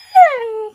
Minecraft Version Minecraft Version 1.21.5 Latest Release | Latest Snapshot 1.21.5 / assets / minecraft / sounds / mob / wolf / classic / whine.ogg Compare With Compare With Latest Release | Latest Snapshot
whine.ogg